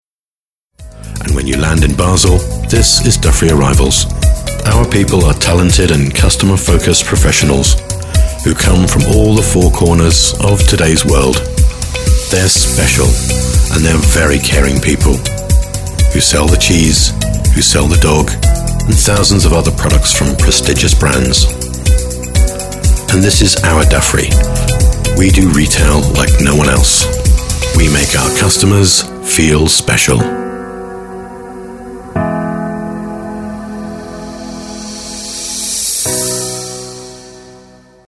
Engels (Brits)
Diep, Opvallend, Volwassen, Warm, Zakelijk
Corporate